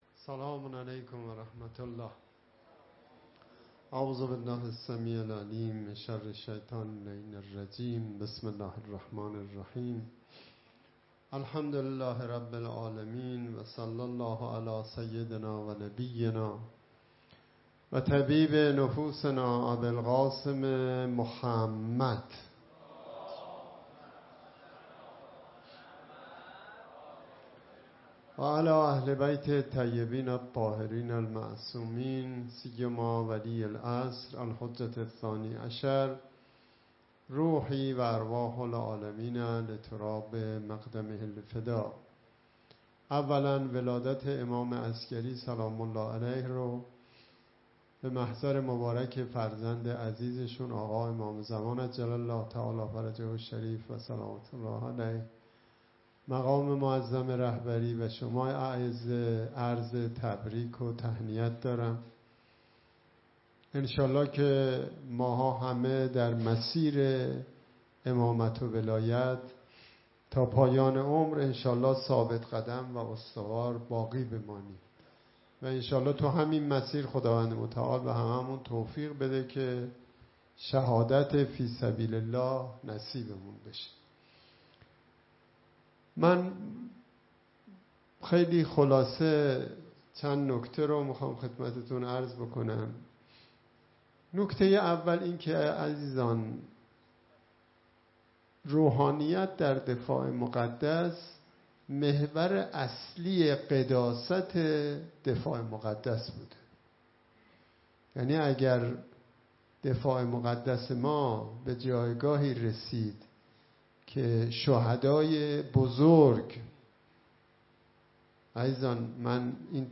سخنرانی
در همایش" مبلغان مجاهد"